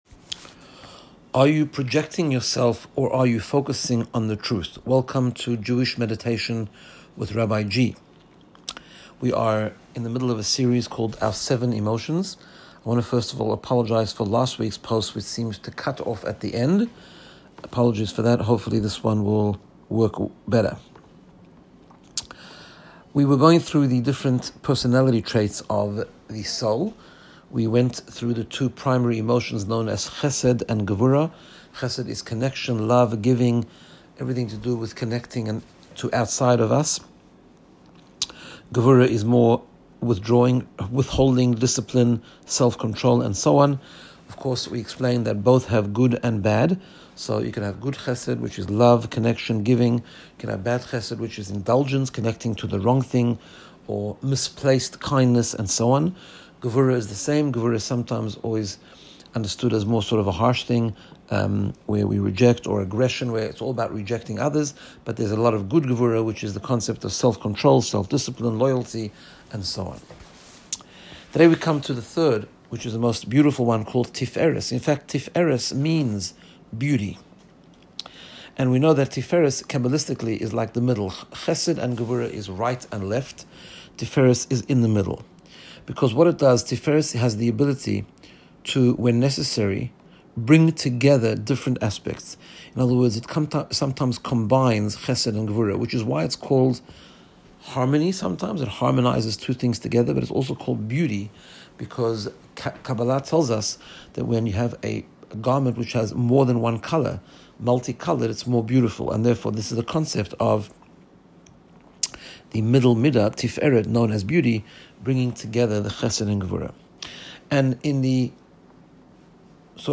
Guided Meditation to get you in the space of Personal Growth and Wellbeing. In this episode we learn about Tiferet- Harmony and Beauty.